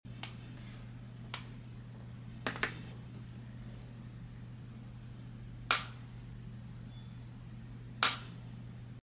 Galaxy Z Fold 5 Clapping sound effects free download
Galaxy Z Fold 5 Clapping Too Soft